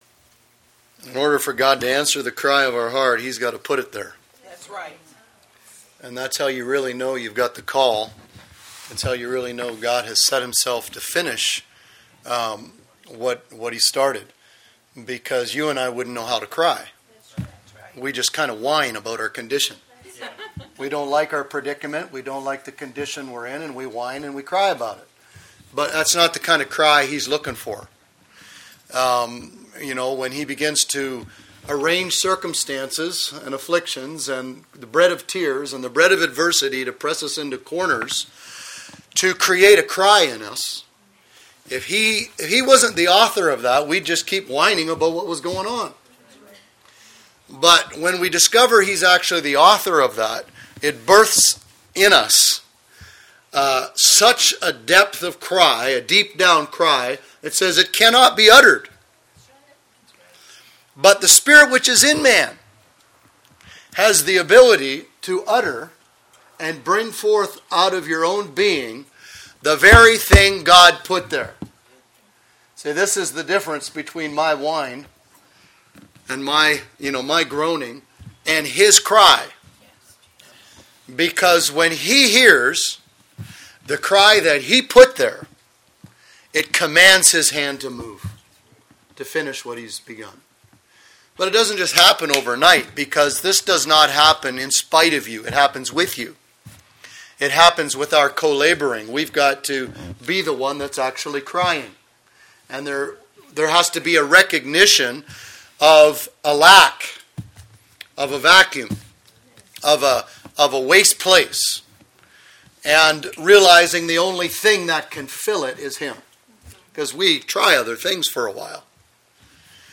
Posted in Teachings